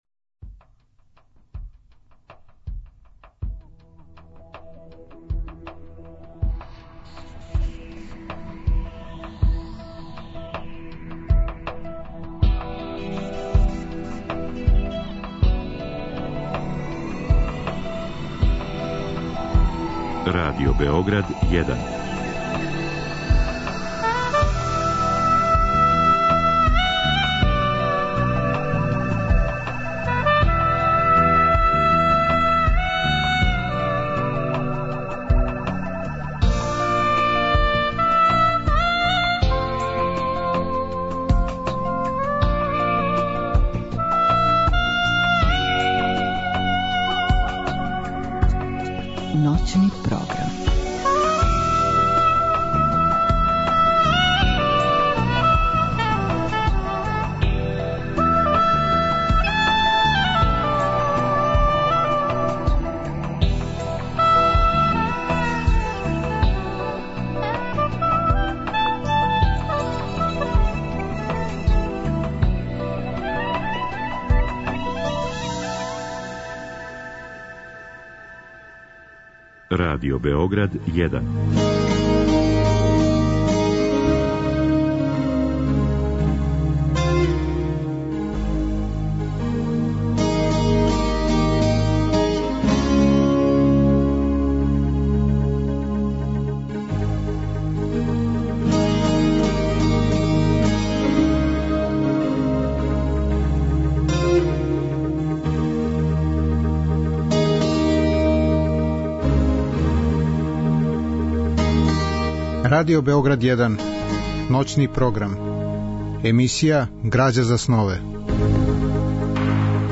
Разговор и добра музика требало би да кроз ову емисију и сами постану грађа за снове.
У другом делу емисије, од два до четири часа ујутро, слушаћемо одабране текстове из чувеног дела Стилске вежбе Рејмона Кеноа.